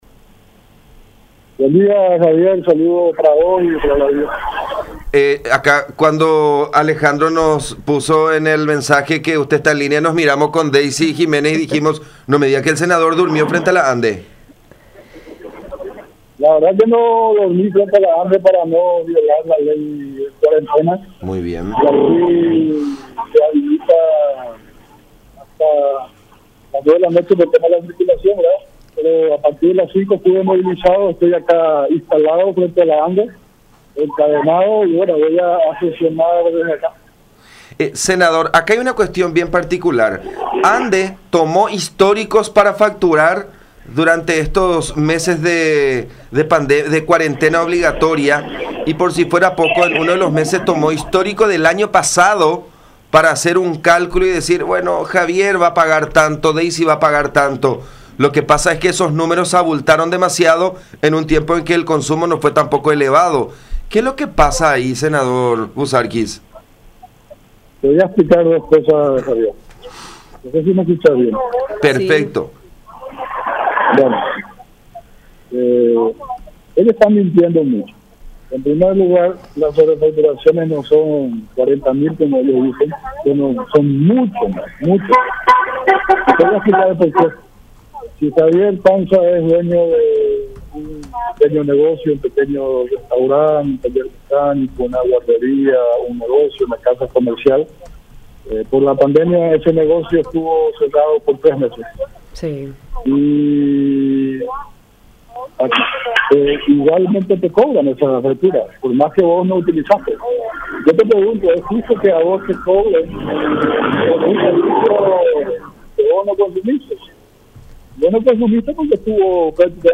“Las sobrefacturaciones son mucho más de 40.000, que es el número que la ANDE dice. No te pueden cobrar lo que vos no consumiste, eso es irrefutable. La ANDE nos está mintiendo”, expresó Salyn Buzarquis en contacto con La Unión, en referencia al cálculo estimativo de los últimos 12 meses que utilizó la empresa estatal para realizar la emisión de las facturas, lo cual provocó sobrecostos en miles de usuarios.